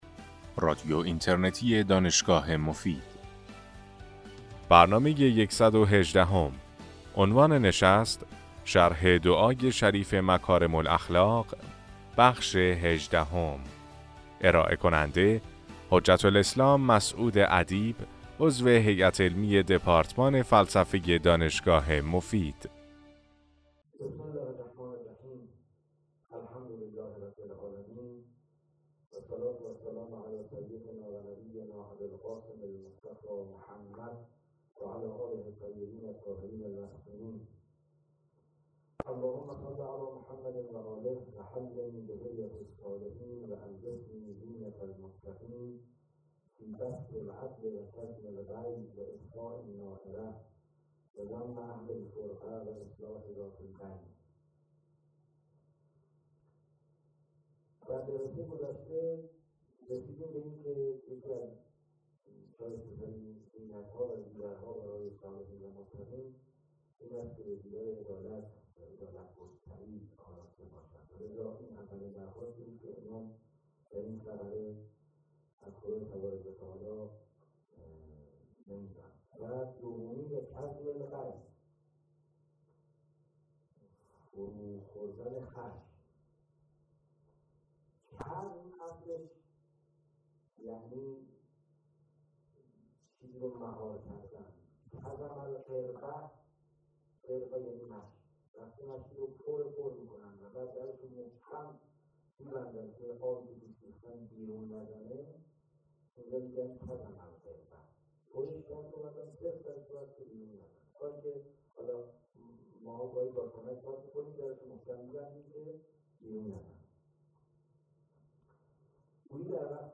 در این سلسله سخنرانی که در ماه رمضان سال 1395 ایراد شده است